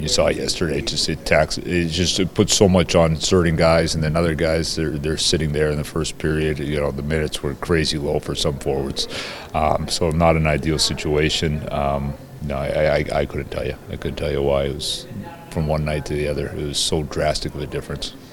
At practice yesterday, Pens Head Coach Dan Muse was asked about the sudden spike in the number of penalties in the game against the Red Wings Thursday night.  The game before, in Chicago, the Pens remained out of the penalty box, but they were assessed eight penalties on Thursday.  As to why that happened, he’s as confused as anyone else.